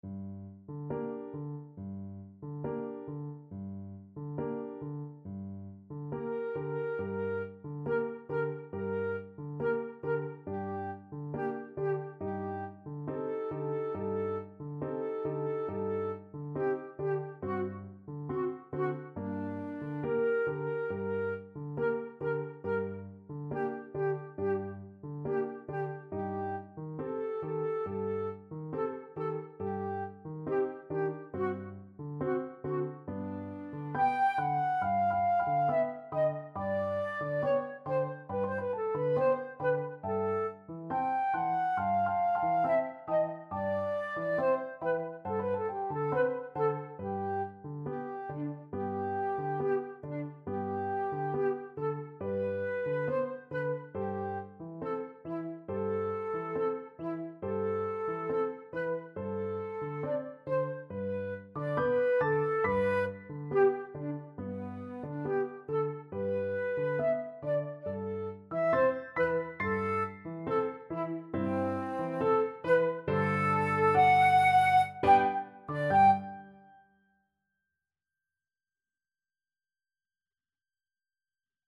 2/4 (View more 2/4 Music)
G minor (Sounding Pitch) (View more G minor Music for Flute Duet )
Allegretto quasi Andantino =69 (View more music marked Andantino)
Flute Duet  (View more Intermediate Flute Duet Music)
Classical (View more Classical Flute Duet Music)